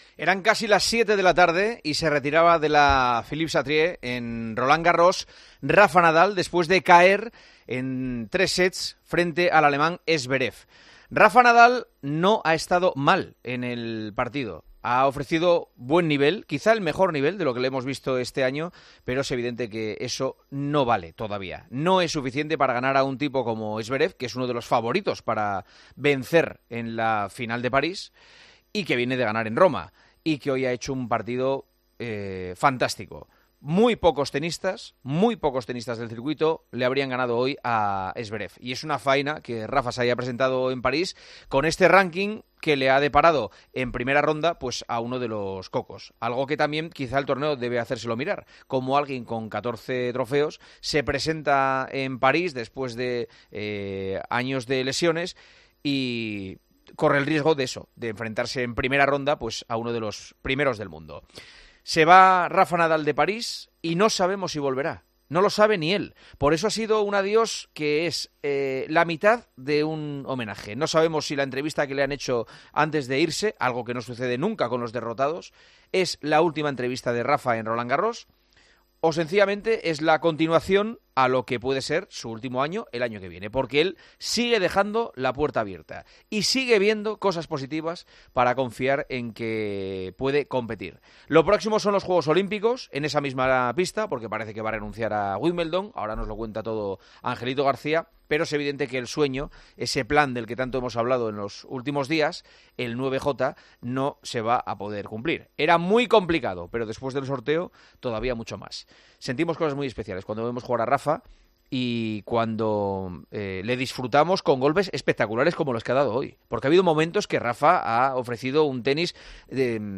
El presentador de El Partidazo ha iniciado el programa con la derrota de Nadal en primera ronda de Roland Garros y se ha mostrado sorprendido con el nivel del tenista español.